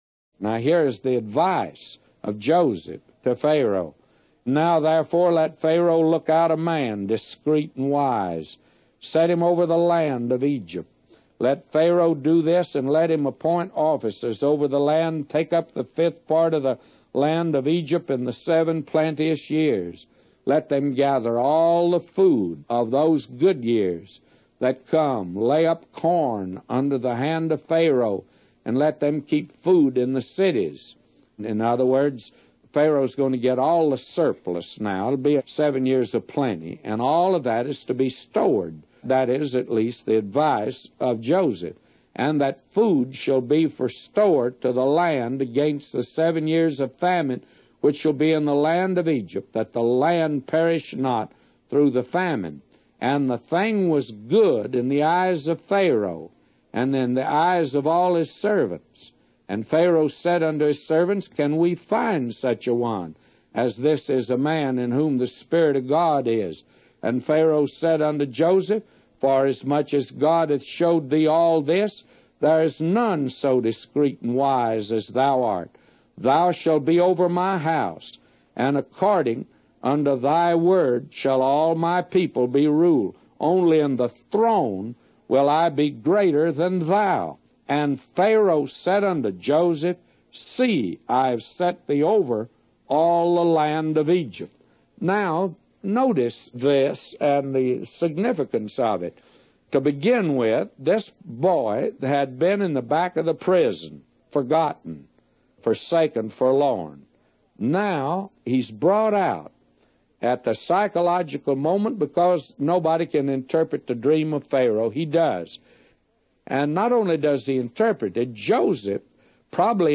A Commentary By J Vernon MCgee For Genesis 41:33-999